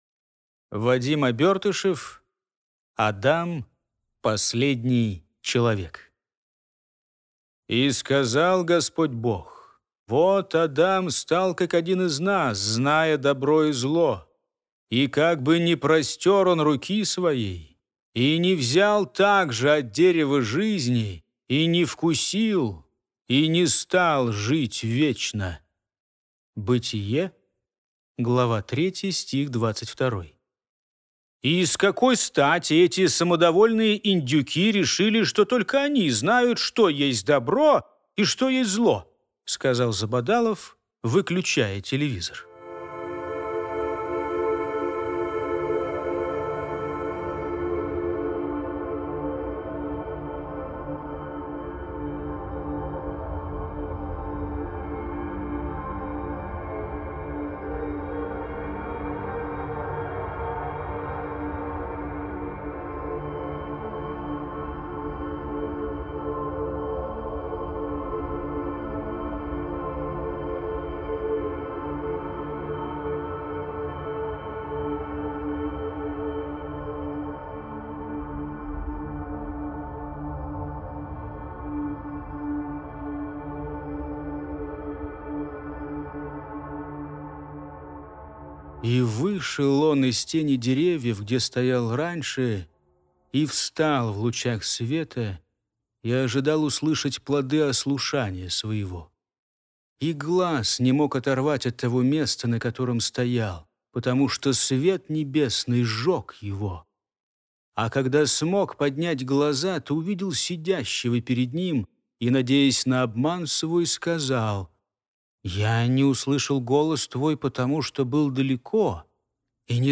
Аудиокнига Адам, последний человек | Библиотека аудиокниг